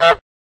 honk.wav